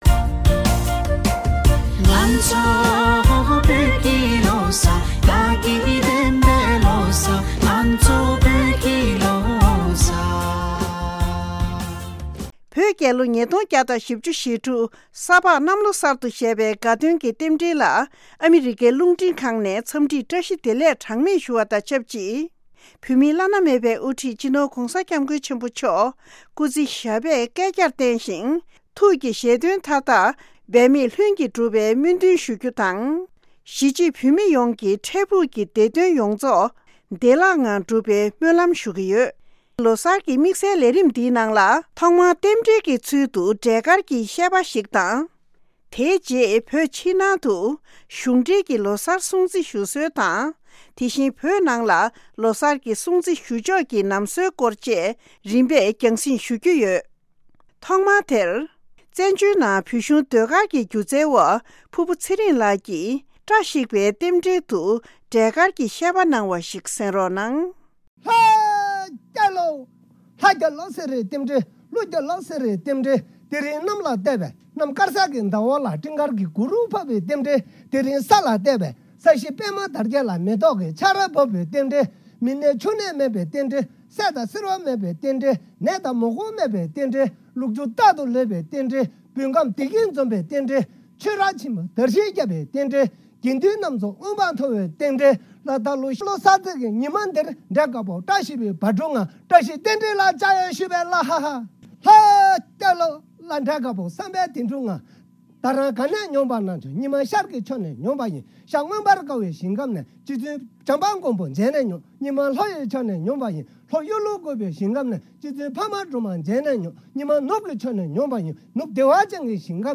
འབྲེལ་ཡོད་མི་སྣར་བཅར་འདྲི་ཞུས